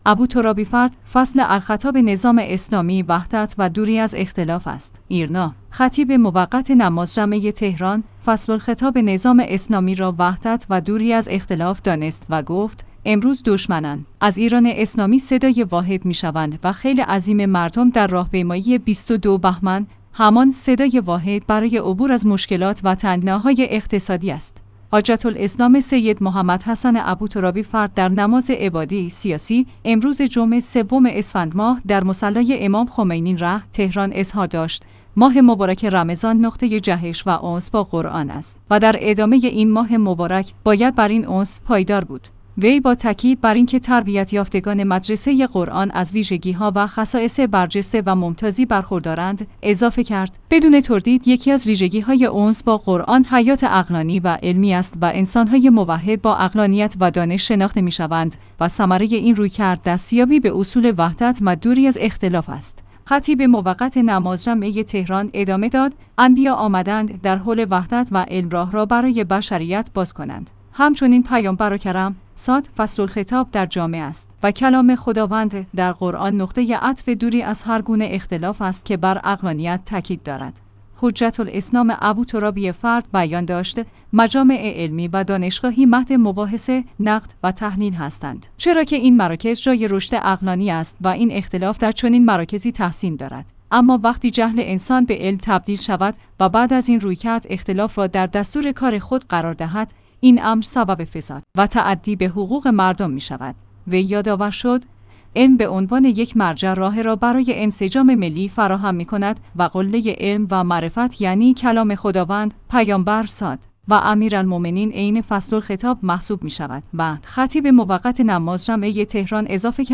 ایرنا/ خطیب موقت نماز جمعه تهران، فصل‌الخطاب نظام اسلامی را وحدت و دوری از اختلاف دانست و گفت: امروز دشمنان، از ایران اسلامی صدای واحد می‌شوند و خیل عظیم مردم در راهپیمایی ۲۲ بهمن، همان صدای واحد برای عبور از مشکلات و تنگناهای اقتصادی است.